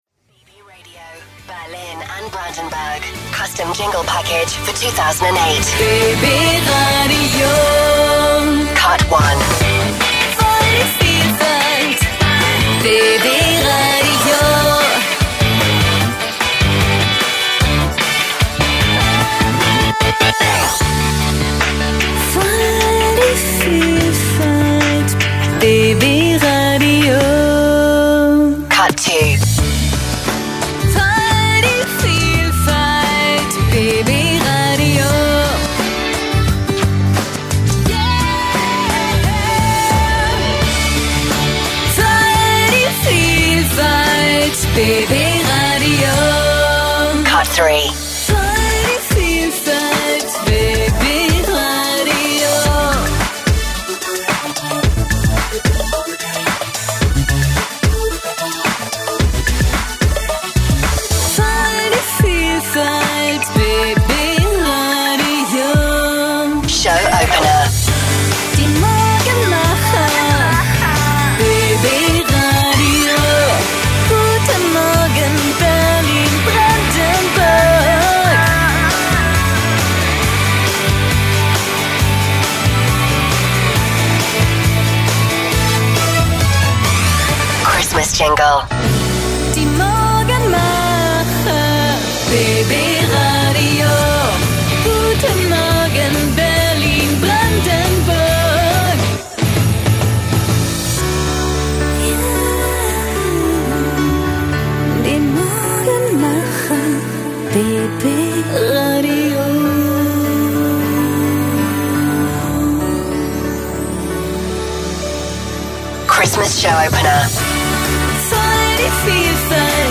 profiSprecherin - voiceArtist - ISDN-Studio - Stationvoice, TV-Offsprecherin
Sprechprobe: Sonstiges (Muttersprache):
german female voice over artist with professional ISDN-studio - Stationvoice, well-known TV-Voice, warm, believable german voicetalent, Off-voice, VoiceOver, radiospots, audiobooks and more.